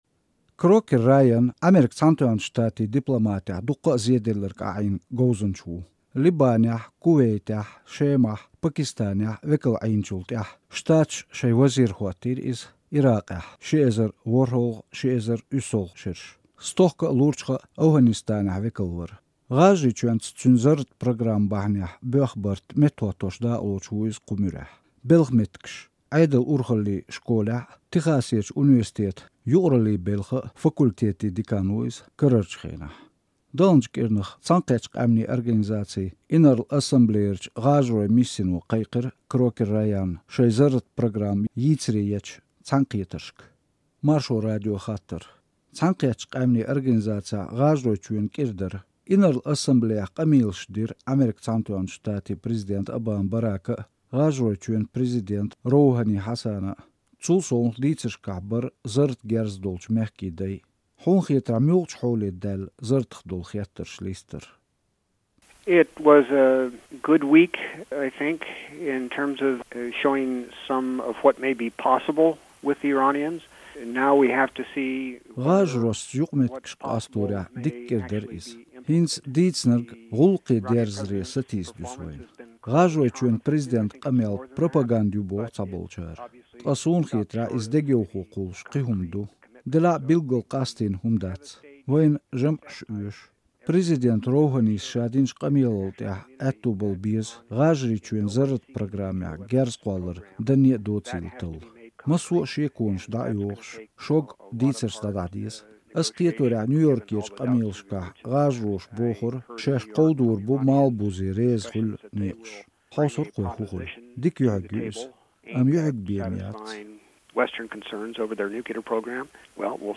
Дипломатца Крокер Райанца интервью